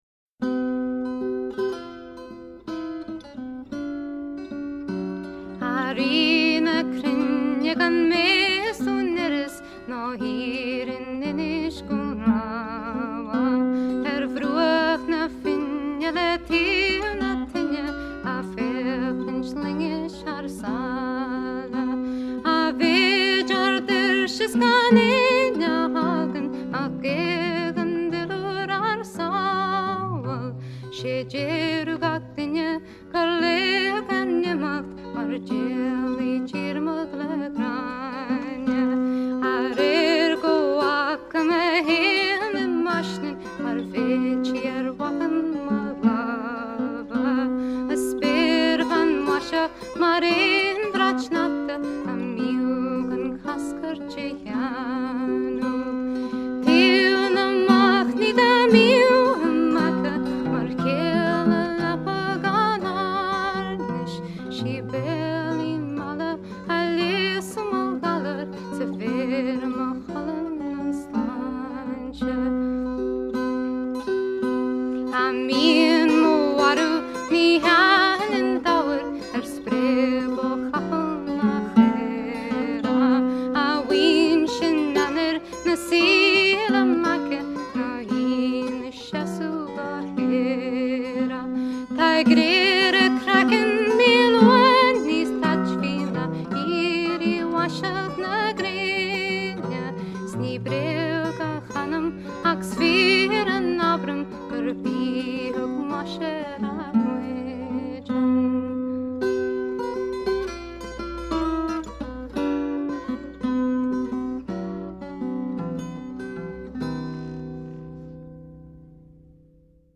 Guitar, Vocals
Harpsichord, Vocals